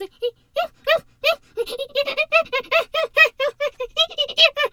pgs/Assets/Audio/Animal_Impersonations/hyena_laugh_05.wav at master
hyena_laugh_05.wav